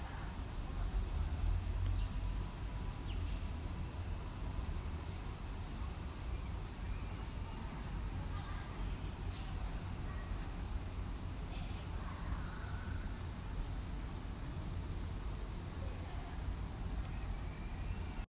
【環境音】夏の音